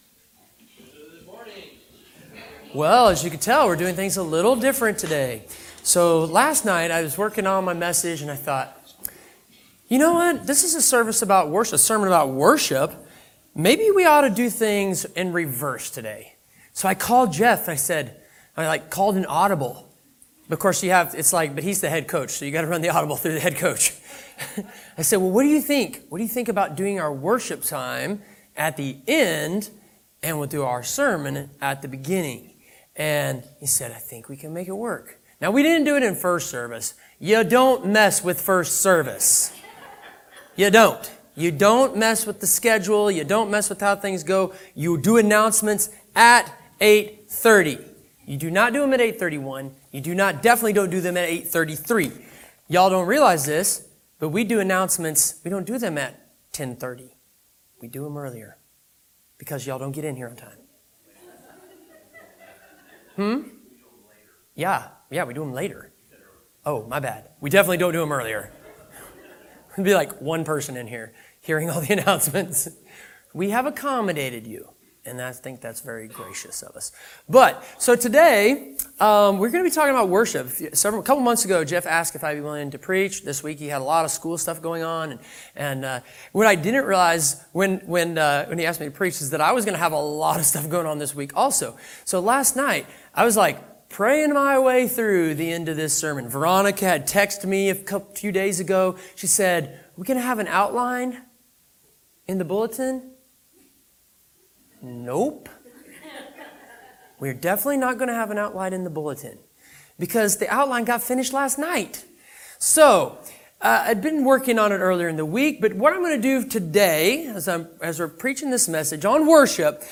Sermon Summary One of the key characteristics of a healthy church is that it lives a lifestyle of worship, both as a community together, and as individuals within the church body.